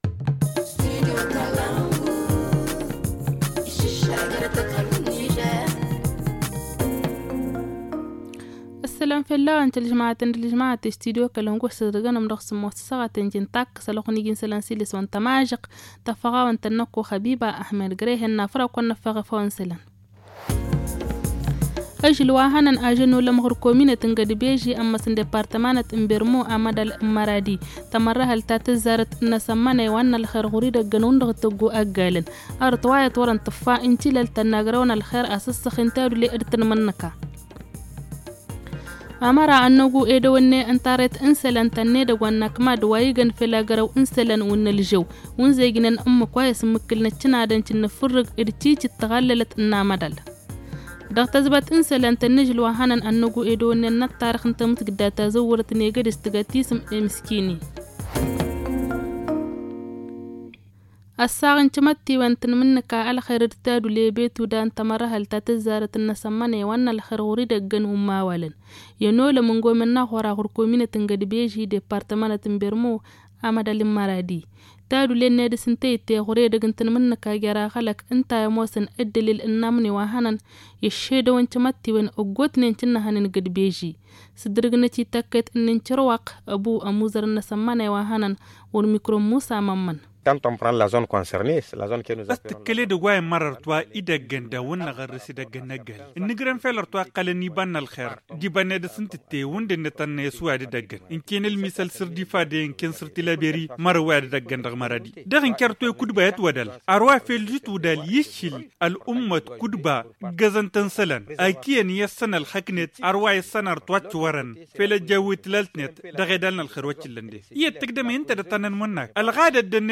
Le journal du 17 mars 2023 - Studio Kalangou - Au rythme du Niger